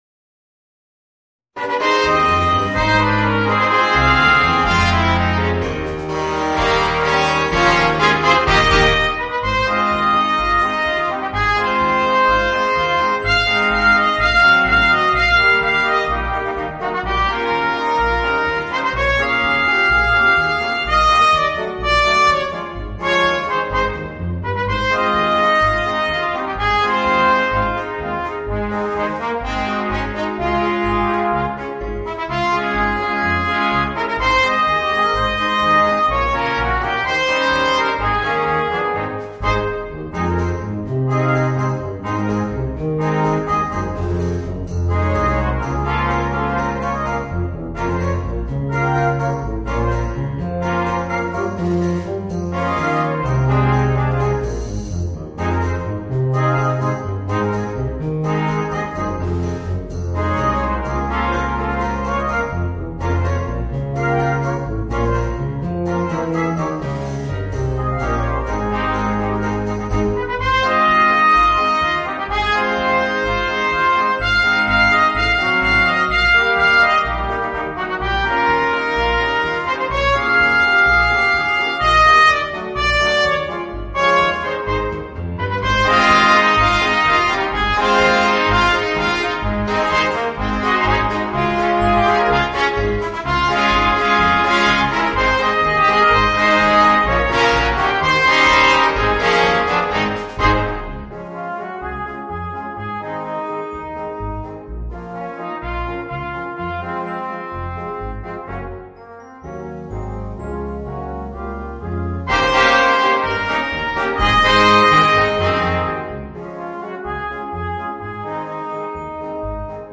Voicing: 3 Trumpets, Trombone and Tuba